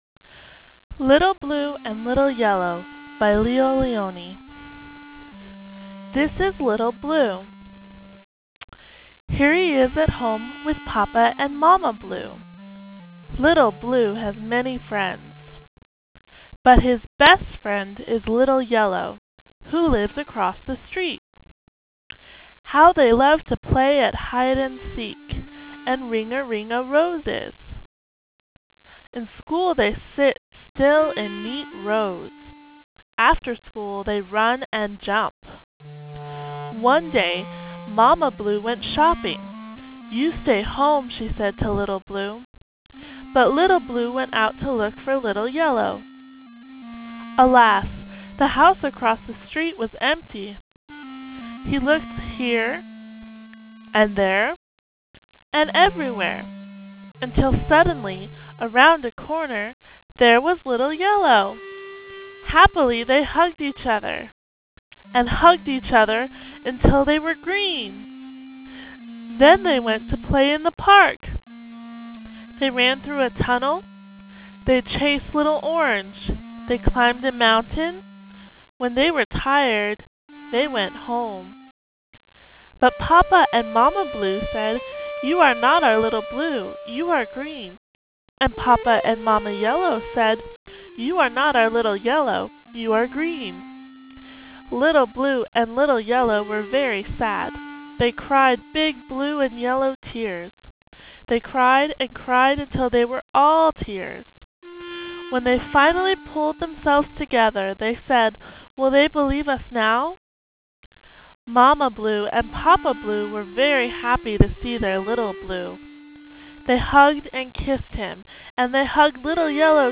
Read aloud.wav